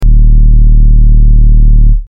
Synthwave Hum
Synthwave_hum.mp3